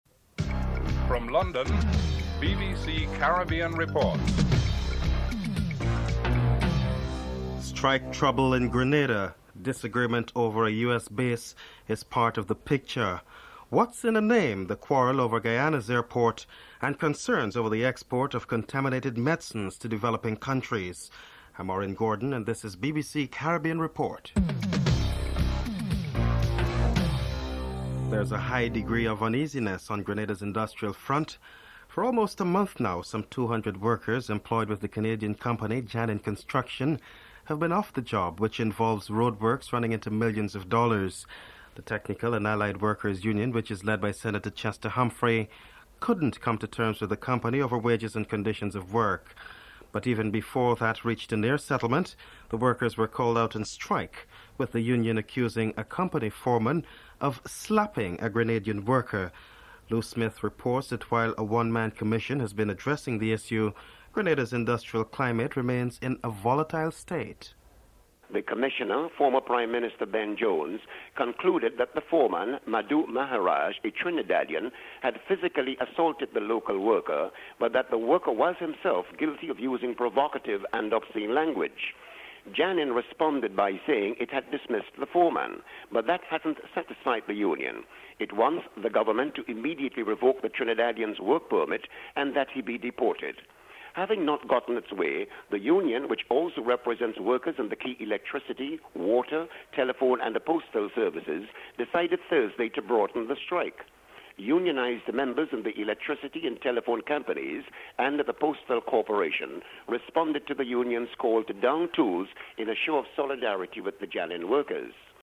1. Headlines (00:00-00:27)
Prime Minister of St Lucia, Dr Vaughn Lewis is interviewed.